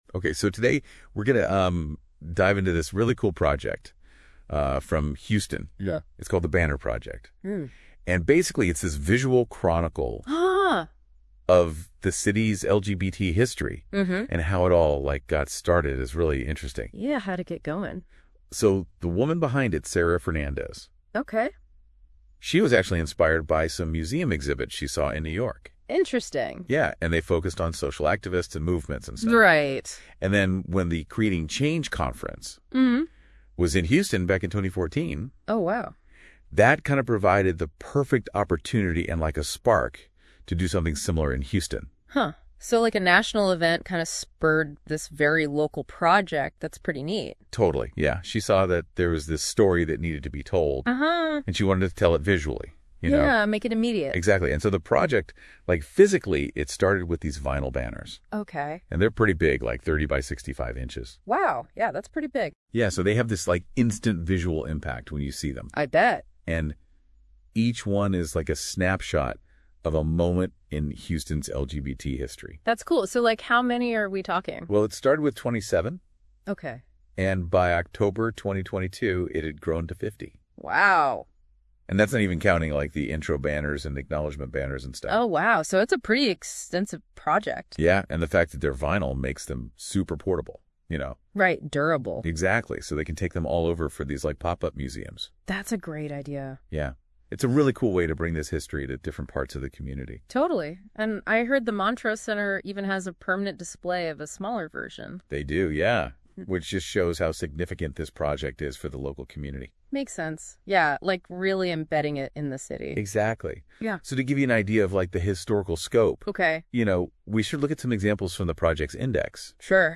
Above, a Quick Overview Talk about the Banner Project, 7:00 min